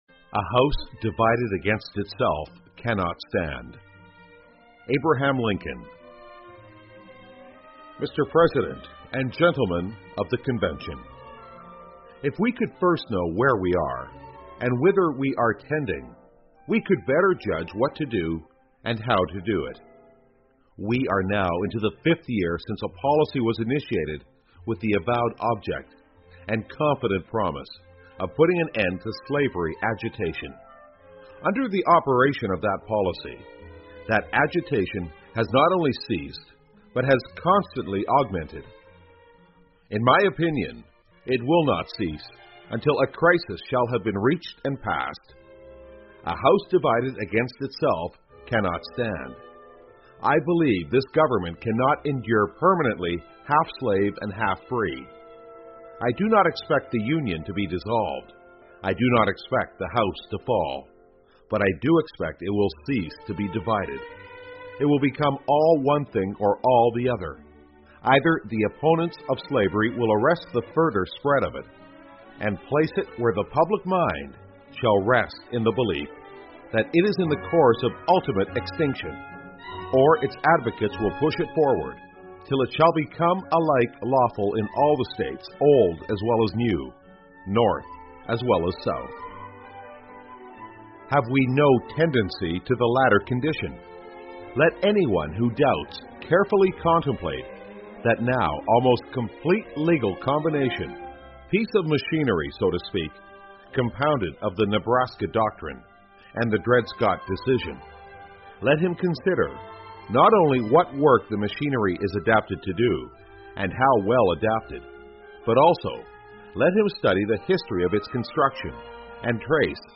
世界上最伟大的演讲--A Divided House cannot stand 家不和,则不立 听力文件下载—在线英语听力室